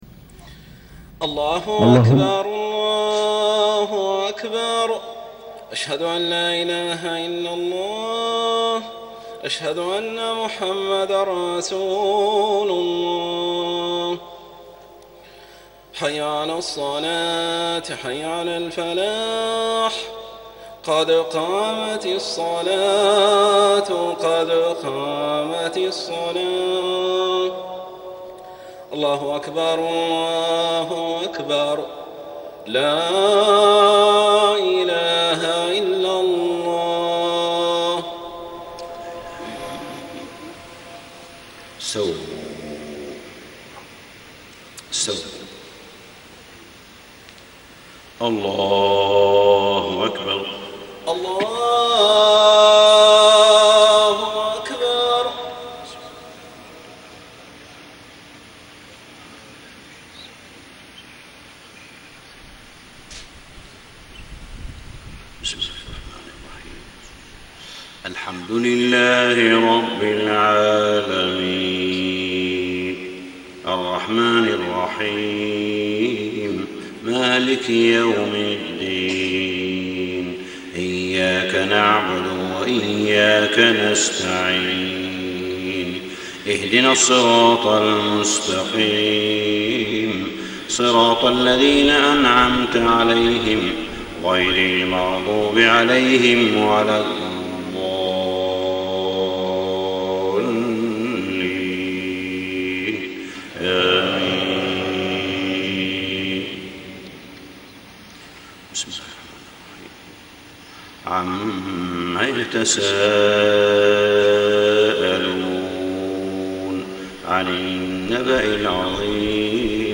صلاة الفجر 4-7-1434هـ سورتي النبأ و الانفطار > 1434 🕋 > الفروض - تلاوات الحرمين